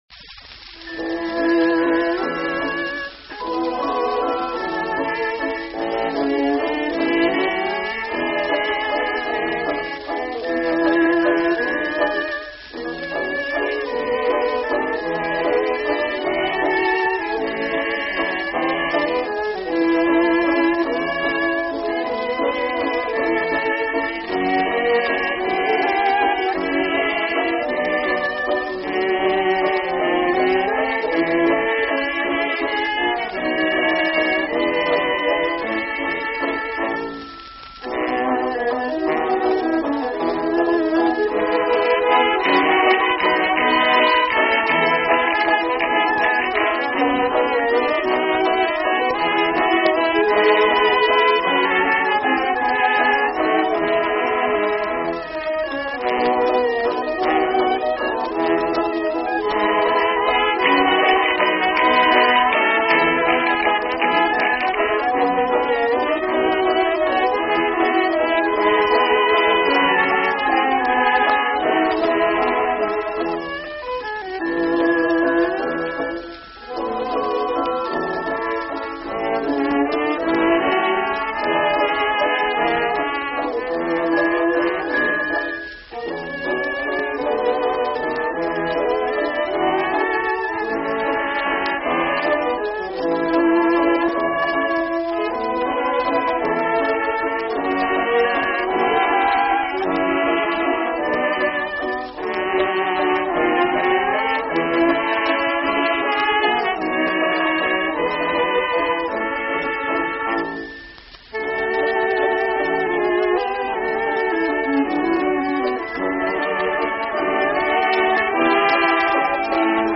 Conductor and Violinist